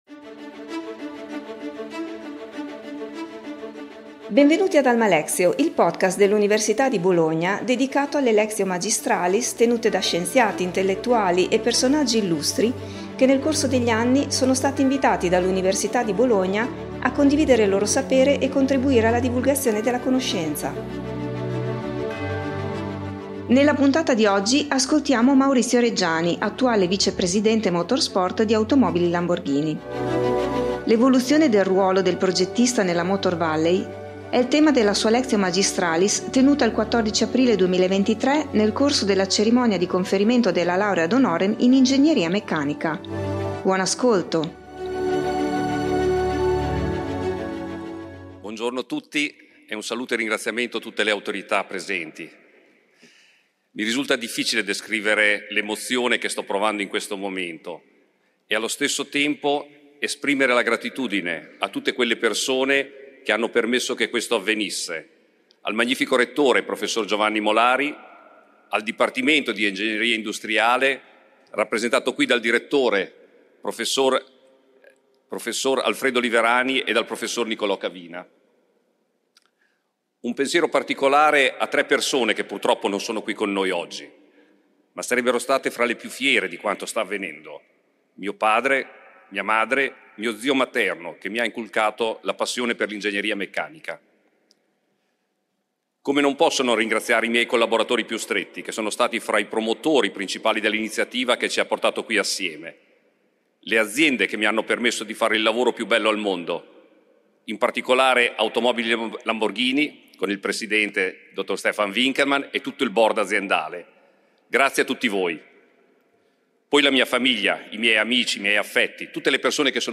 Lectio magistralis